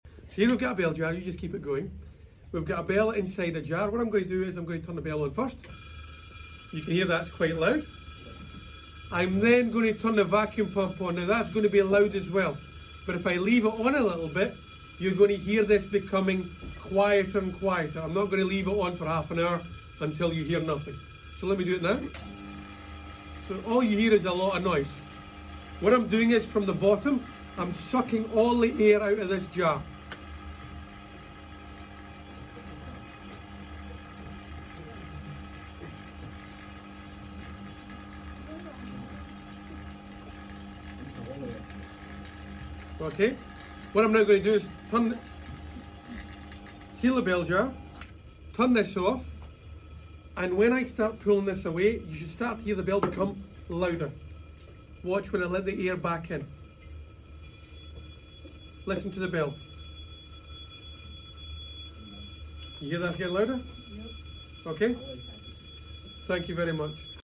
Air is removed from the bell jar and a noticeable decrease in the amplitude (volume) of sound is heard. Yet we can still see the 🔔 ringing therefore light can travel through a vacuum.